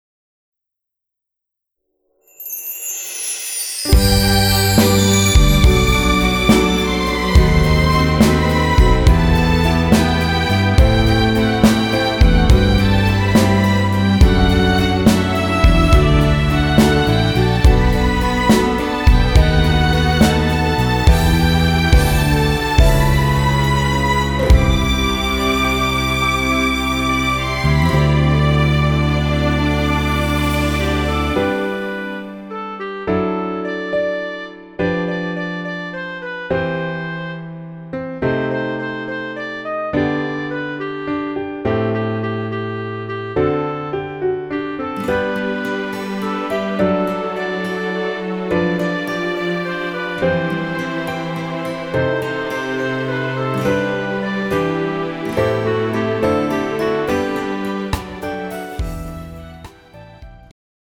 음정 여자키
장르 축가 구분 Pro MR